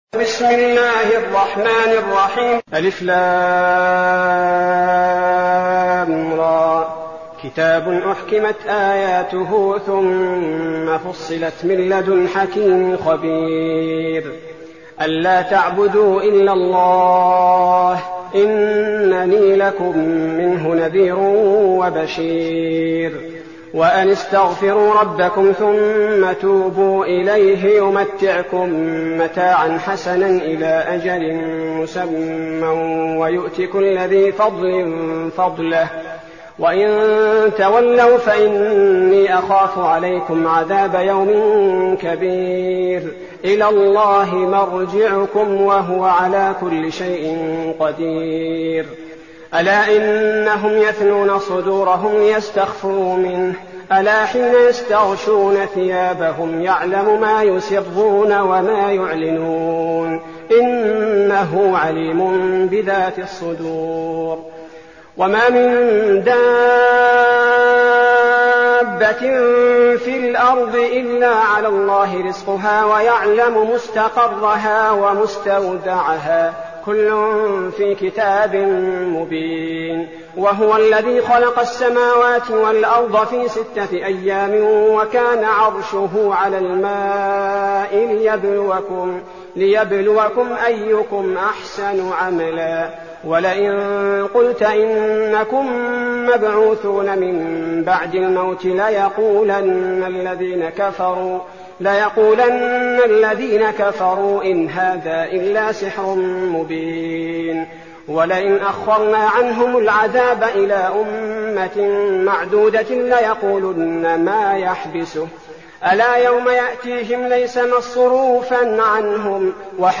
المكان: المسجد النبوي الشيخ: فضيلة الشيخ عبدالباري الثبيتي فضيلة الشيخ عبدالباري الثبيتي هود